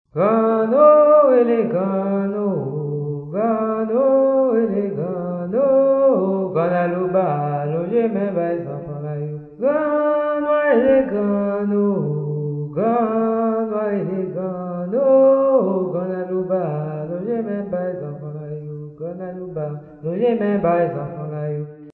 SOME VODOUN SONGS
) Yanvalou